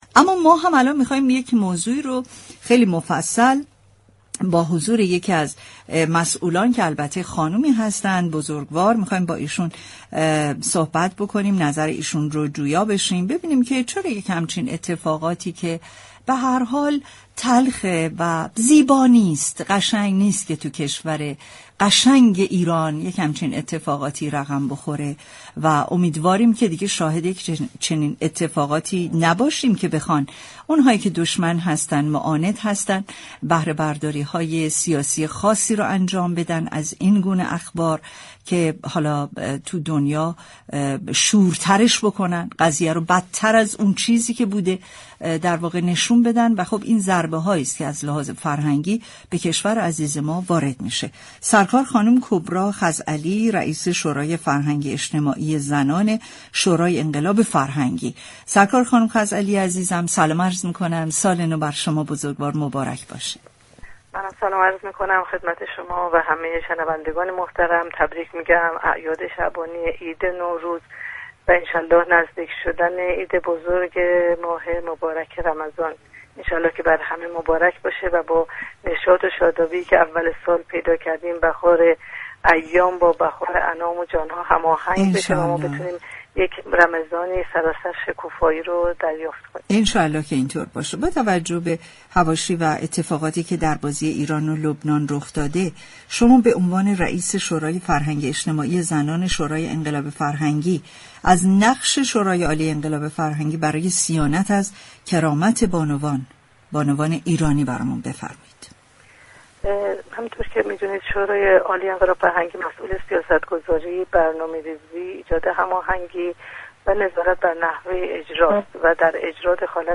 در گفتگوی تلفنی با برنامه سعادت آباد رادیو تهران